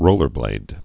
(rōlər-blād)